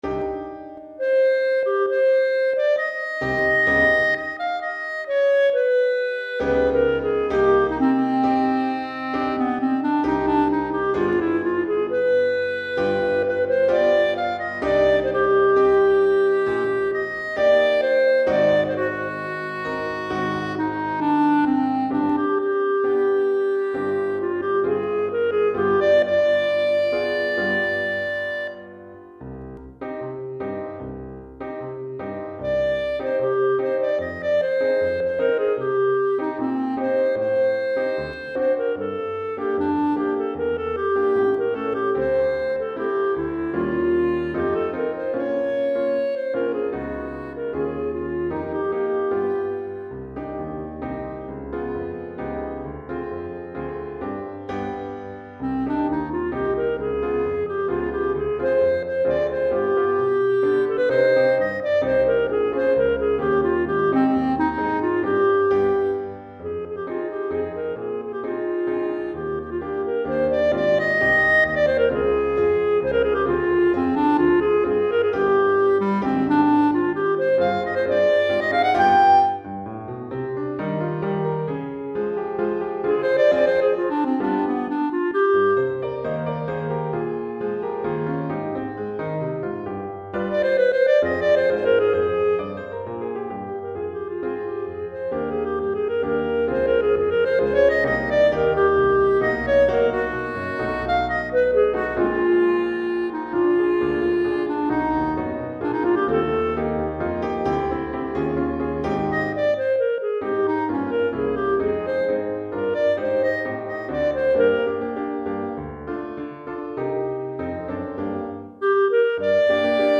Clarinette en Sib et Piano